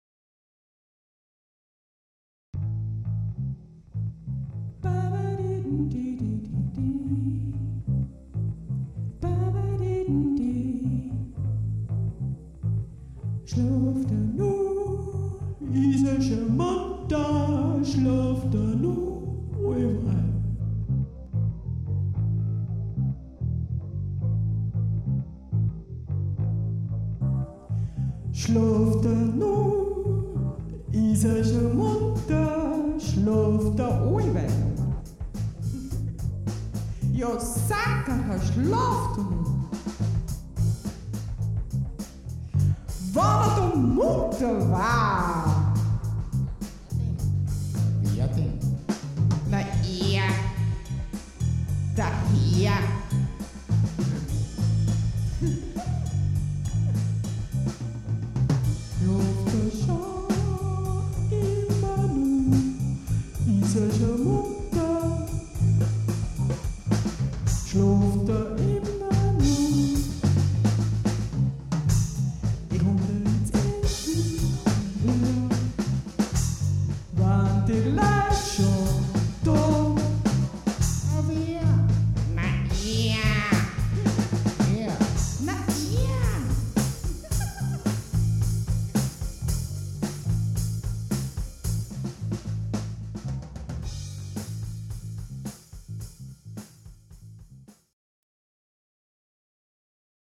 Production No. 7, recorded live.
We have not rehearsed - but played.
We played and recorded everything, for a whole long night.